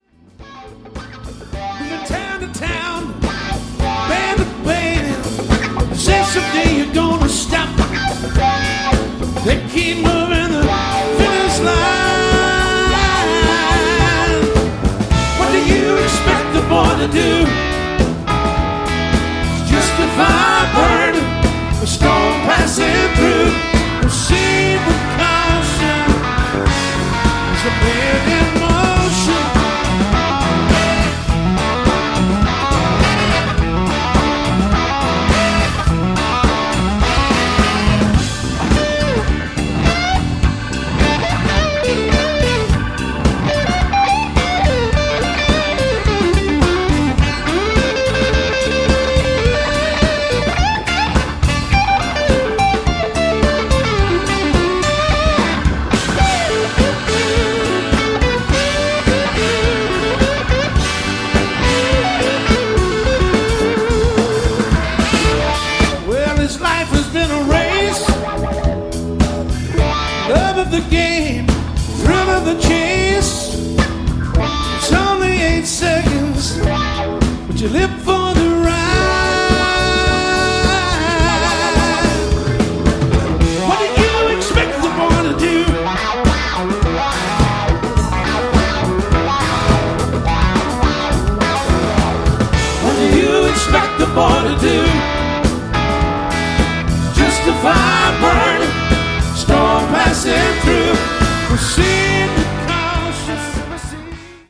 C’est chose faite, et bien faite. Ca groove méchamment.